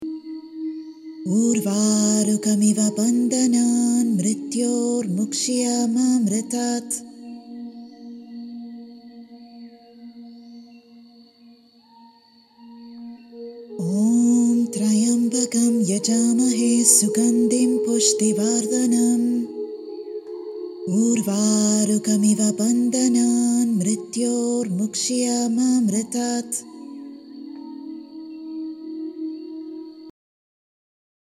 Sanskrit mantra
audio mantra meditation consists of 108 sacred repetitions